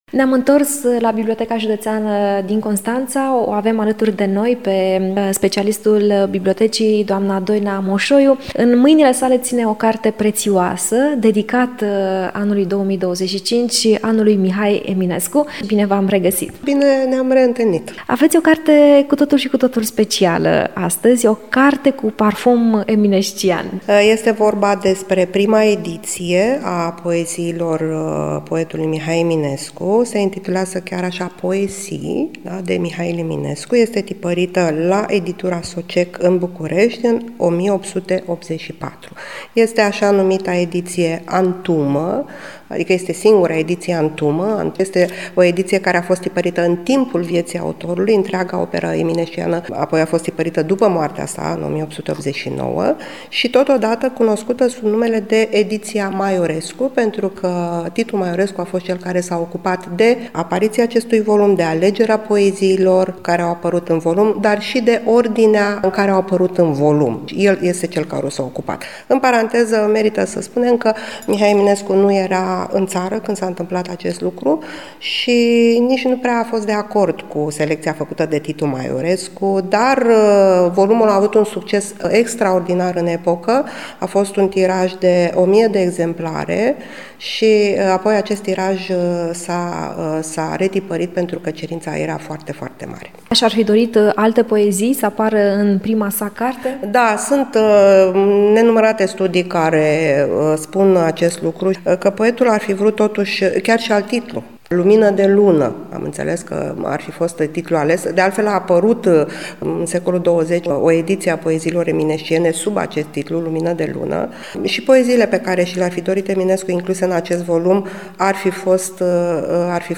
într-un dialog cu